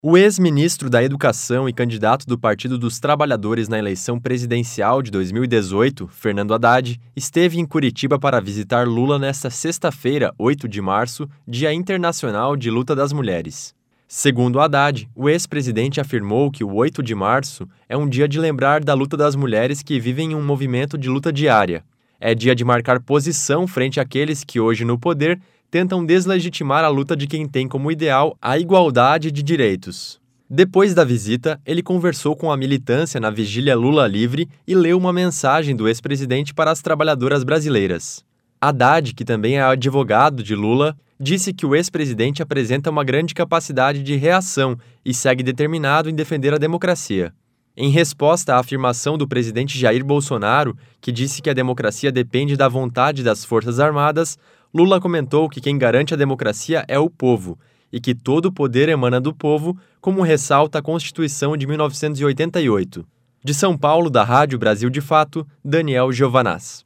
Haddad lê mensagem do ex-presidente na Vigília Lula Livre